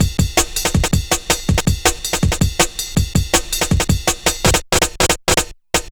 Ride Break 02-162.wav